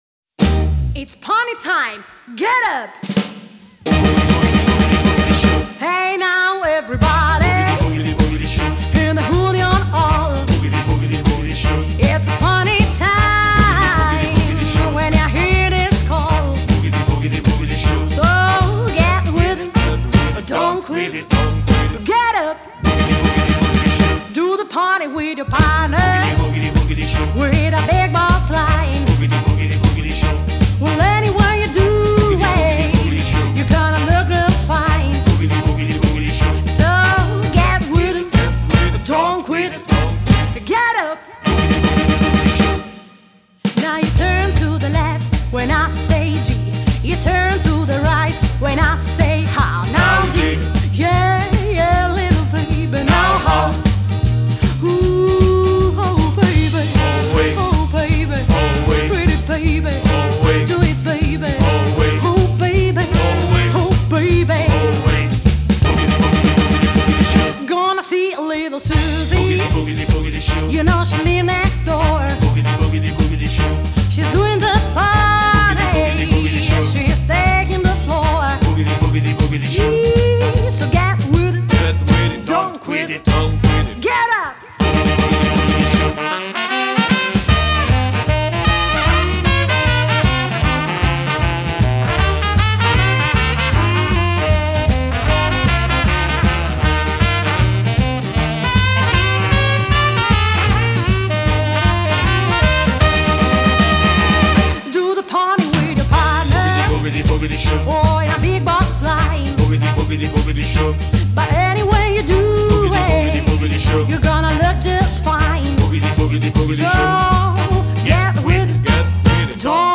Revival ‘50 ‘60 Italia America